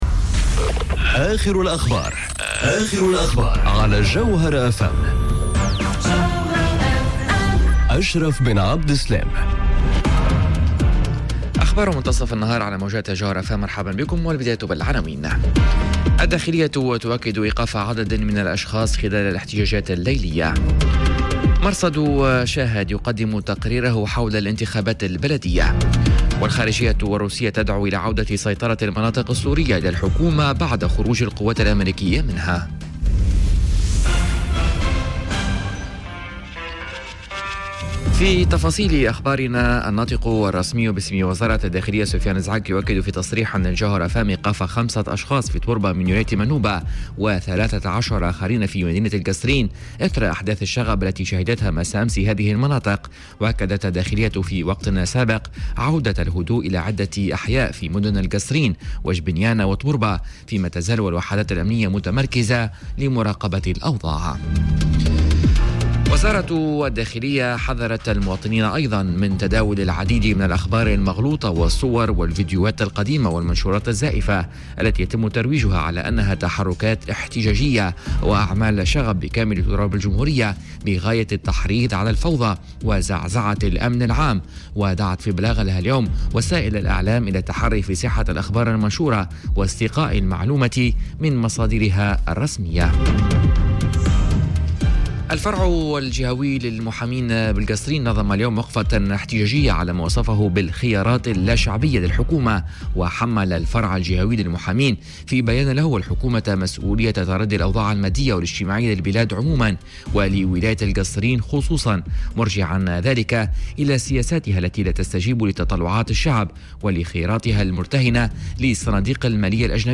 Journal Info 12h00 du mercredi 26 décembre 2018